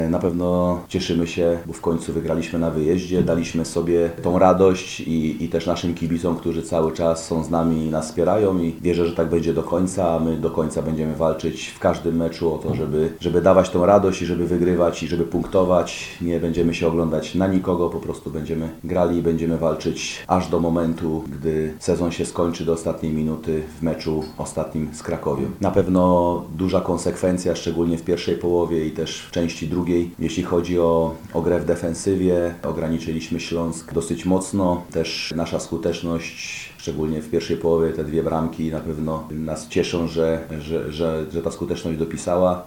Po zwycięstwie swojej drużyny głos zabrał trener Janusz Niedźwiedź.